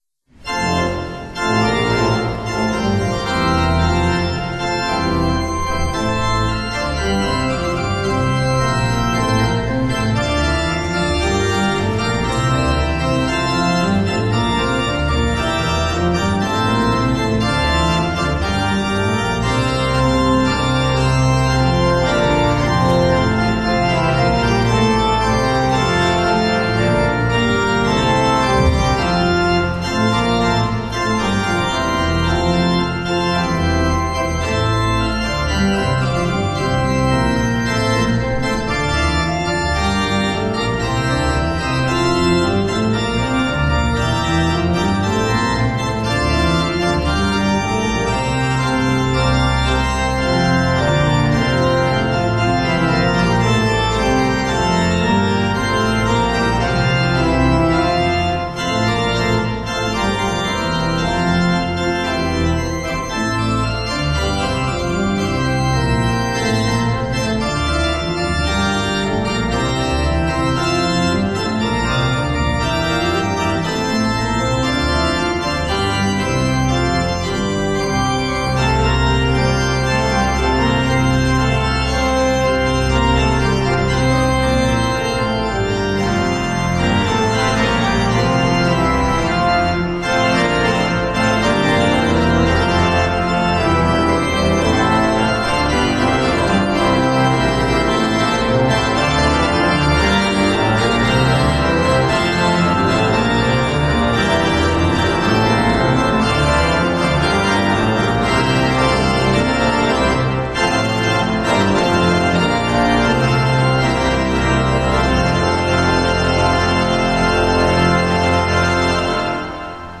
Hear the Bible Study from St. Paul's Lutheran Church in Des Peres, MO, from March 9, 2025.
St. Paul’s Des Peres Bible Study — Matthew 1:18-2:6